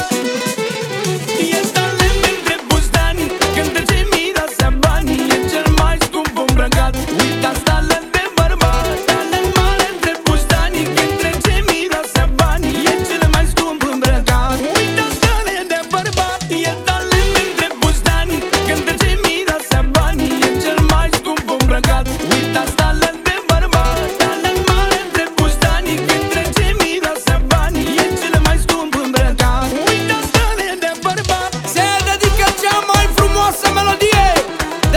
# Vocal